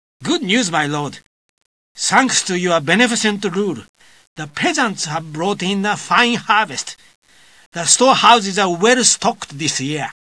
The only difference is, every four seasons, your little assistant will give you a voice breifing on the economic status of the empire....